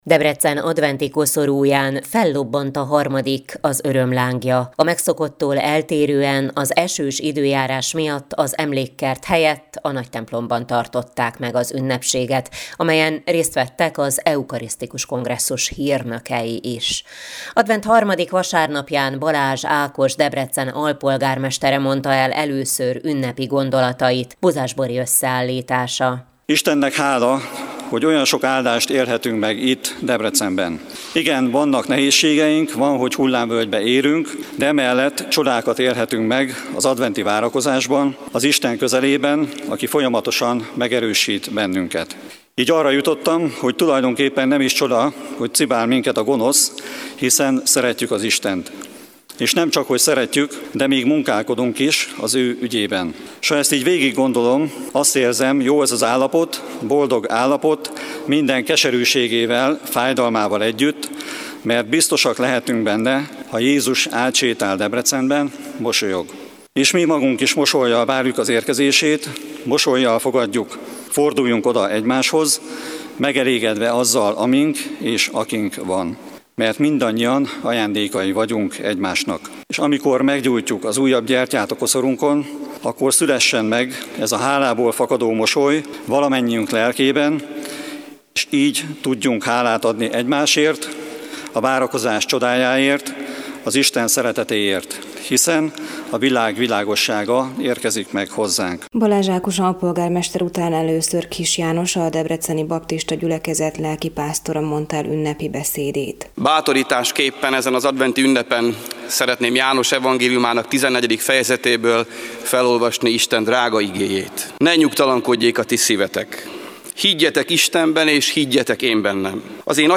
A megszokottól eltérően az esős időjárás miatt, az Emlékkert helyett a Nagytemplomban tartották meg az ünnepséget, amelyen részt vettek az Eukarisztikus Kongresszus hírnökei is.